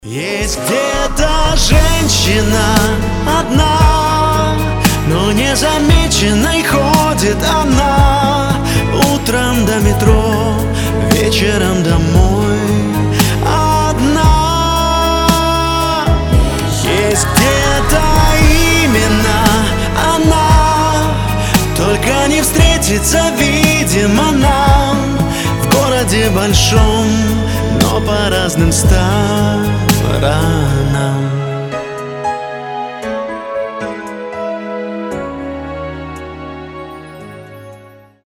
поп
грустные
медляк
нежные
трогательные